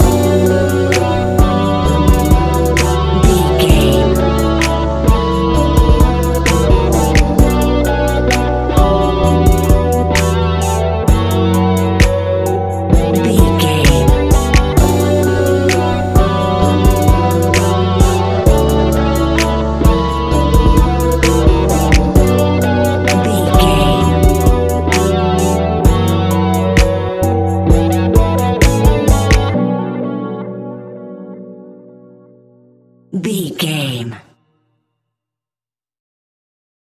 Ionian/Major
B♭
laid back
Lounge
sparse
new age
chilled electronica
ambient
atmospheric